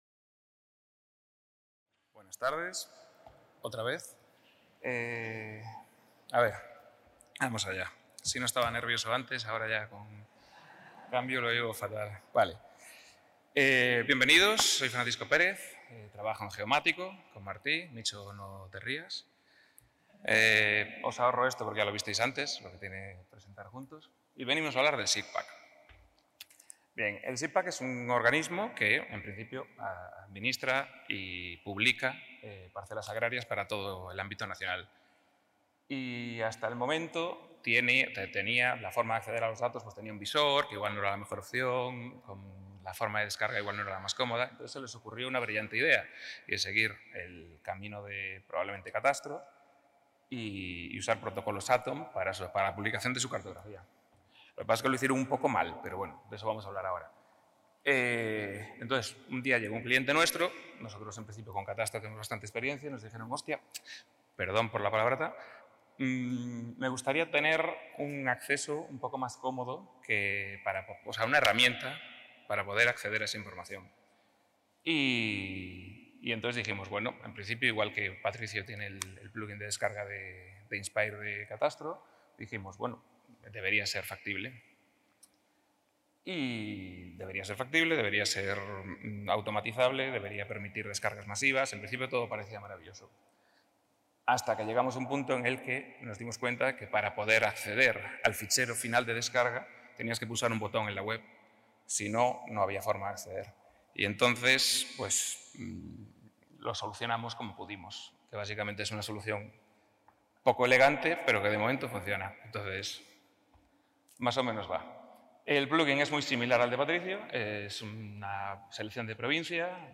Conferència on s'introdueix el Sistema d'Informació Geogràfica de Parcel·les Agrícoles (SIGPAC) i, concretament, s'explica el complement “SIGPAC Downloader” que permet descarregar les parcel·les delimitades pels diferents municipis de l'estat a través del sofware lliure QGIS